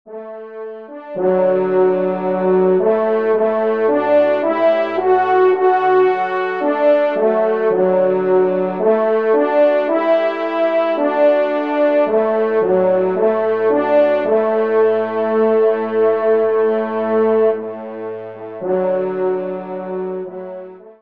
Genre : Musique Religieuse pour Trois Trompes ou Cors
Pupitre 2°Trompe